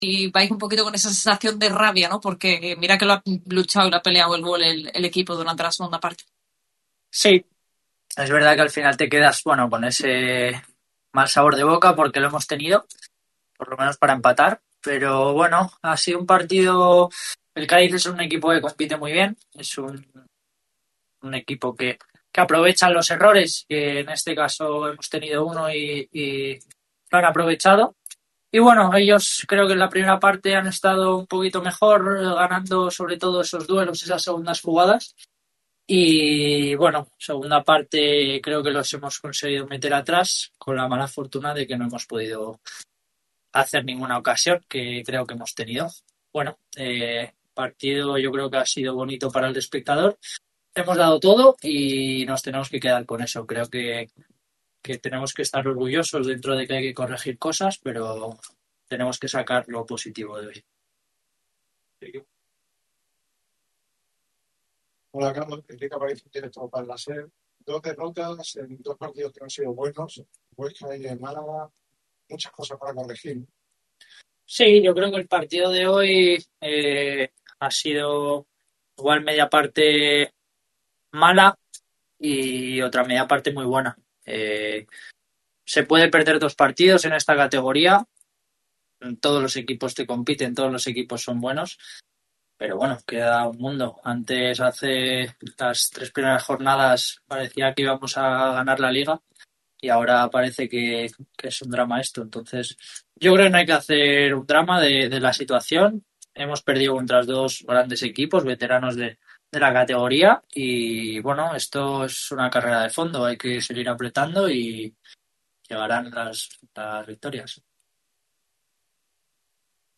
Tras la derrota contra el Cádiz, Carlos Dotor ha comparecido ante los medios en rueda de prensa.